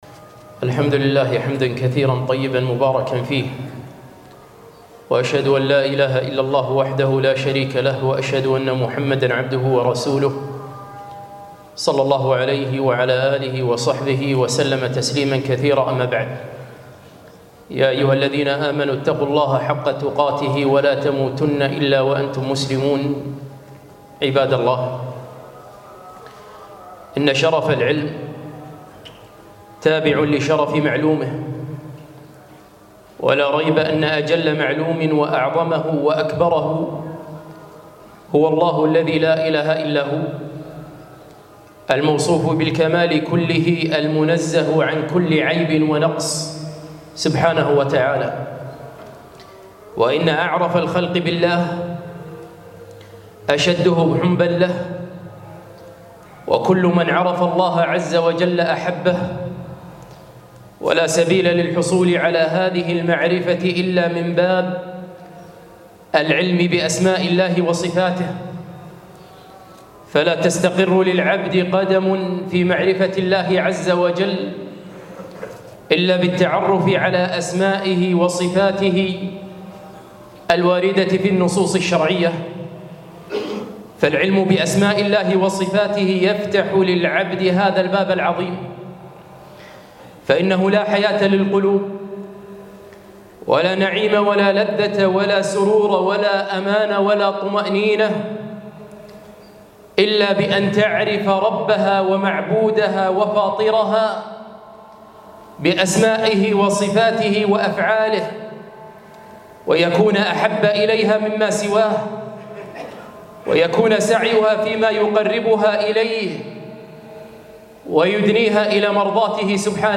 خطبة - إثبات علو الله